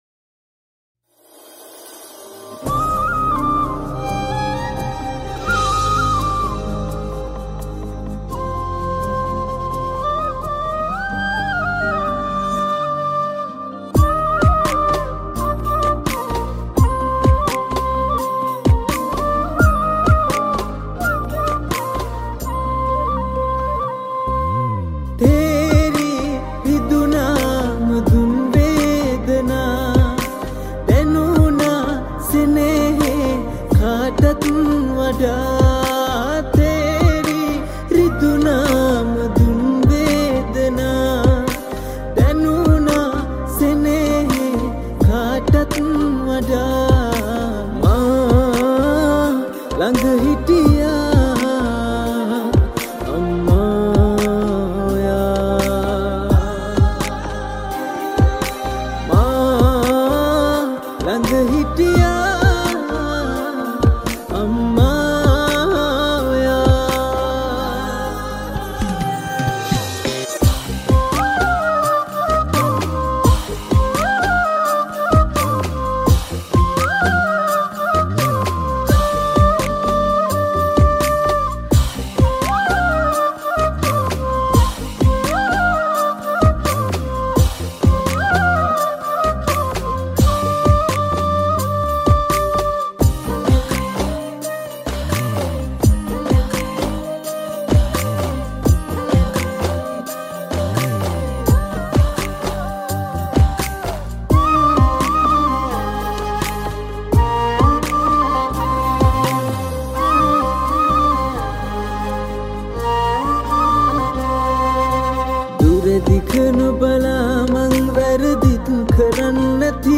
Flutes